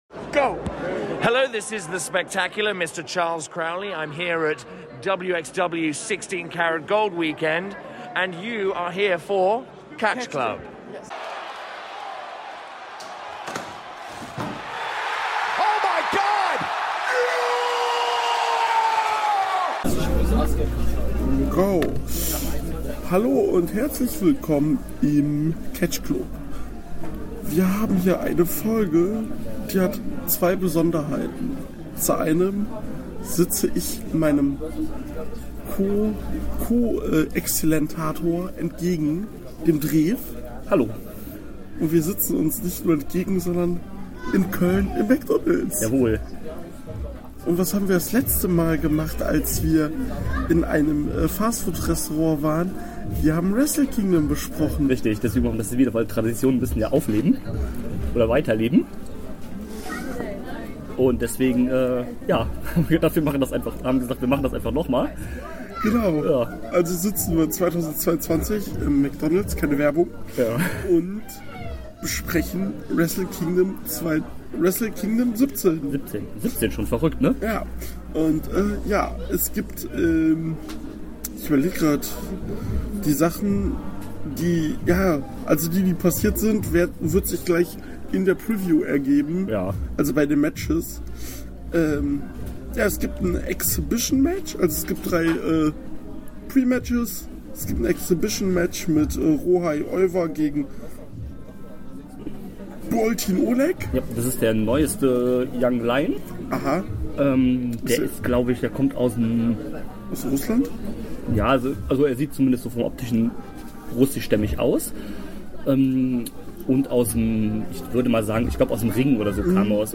Daher Entschuldigung für die Geräuschkulisse.